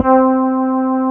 BAS.FRETC4-R.wav